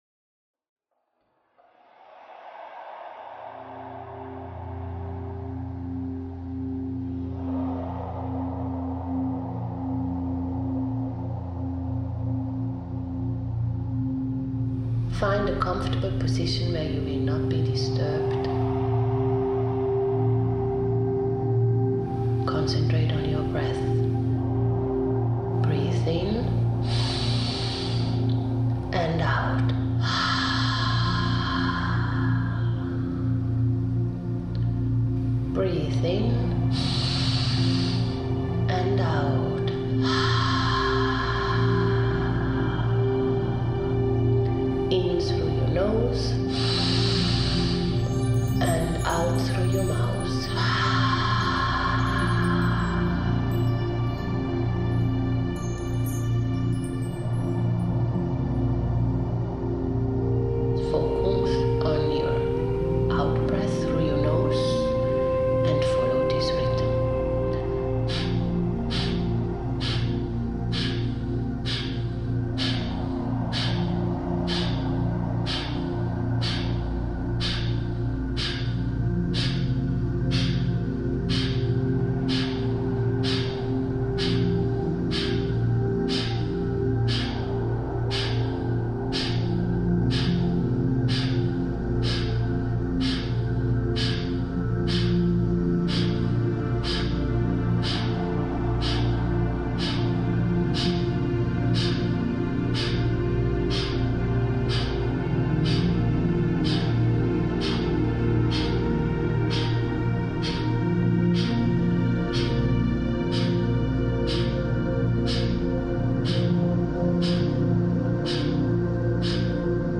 This 23 min. Soma Breathwork journey is a healing and deeply rejuvenating experience, that will keep you supercharged, refreshed, and overdosed with constructive creative inspiration throughout your day 🙂